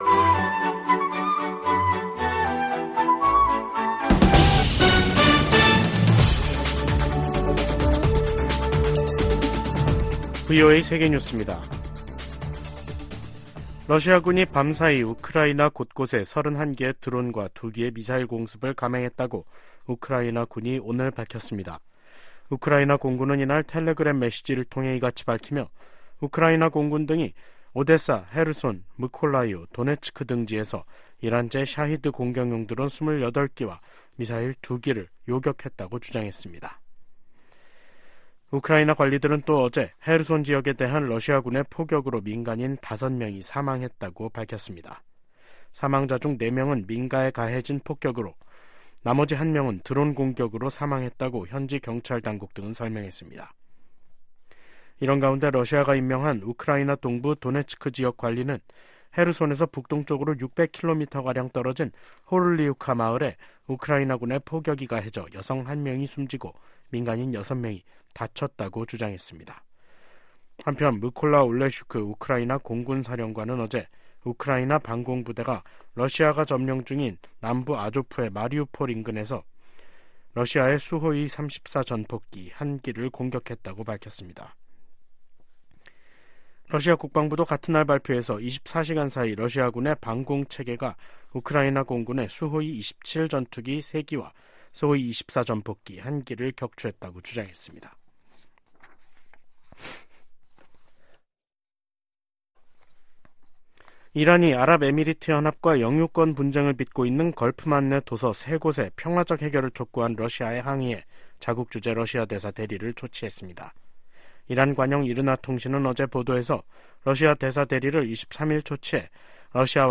VOA 한국어 간판 뉴스 프로그램 '뉴스 투데이', 2023년 12월 25일 2부 방송입니다. 북한이 이번 주 노동당 전원회의를 개최할 것으로 보입니다.